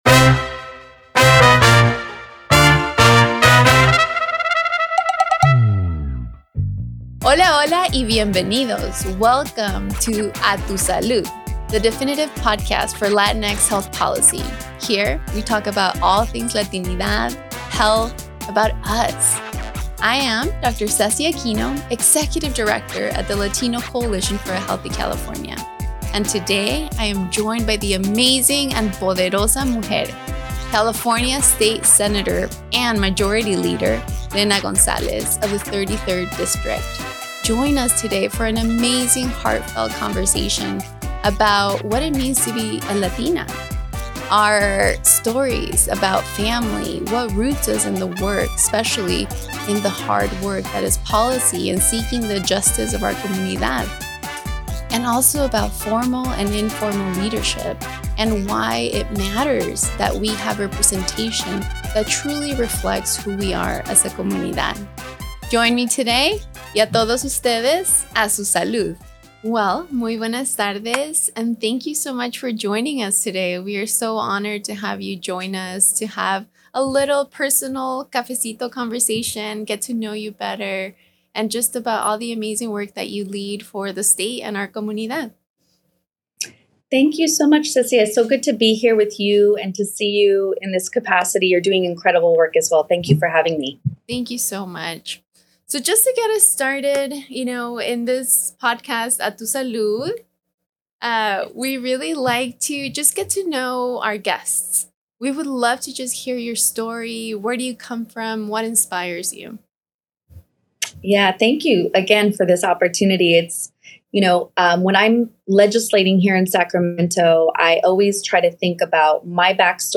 Join us as Senator Gonzalez shares her incredible journey in advancing health policy, discusses the importance of prioritizing Latine and Indigenous communities in state policies, and how her work drives meaningful change to improve health outcomes for ALL Californians!